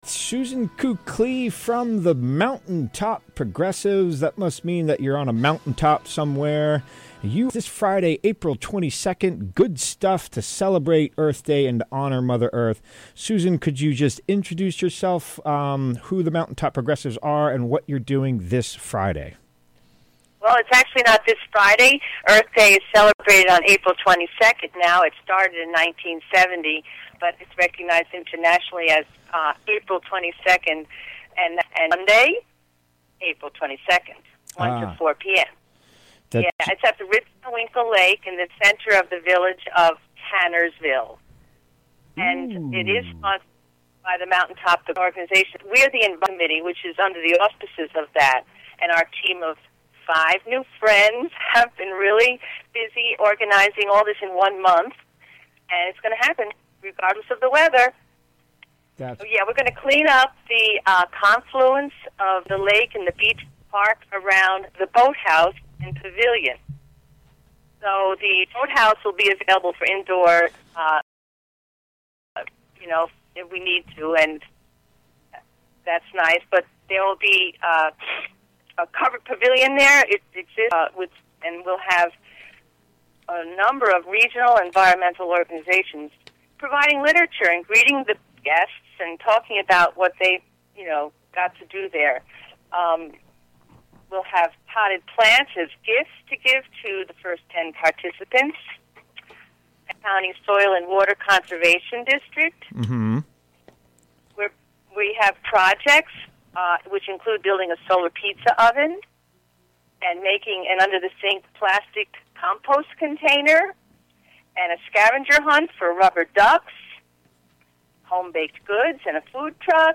Recorded live on the WGXC Afternoon show on April 16, 2018.